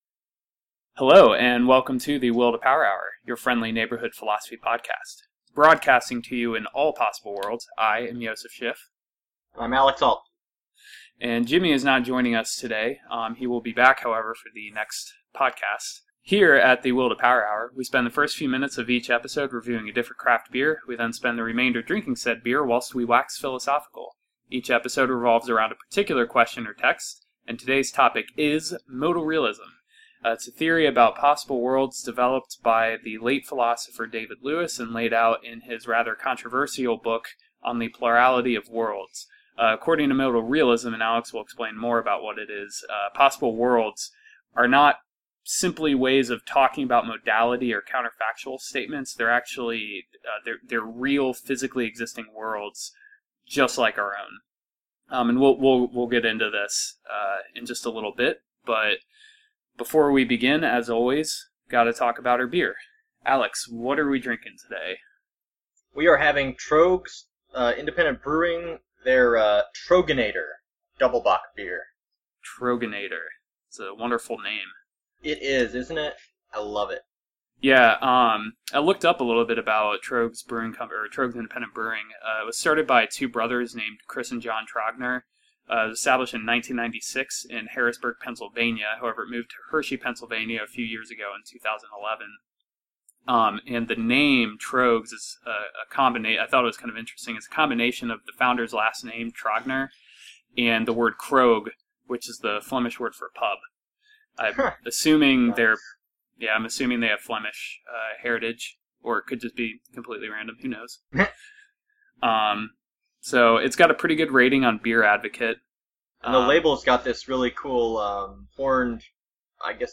Join us as we debate the merits of modal realism while gettin’ drunk on Troegenator Double Bock by Tröegs Independent Brewing!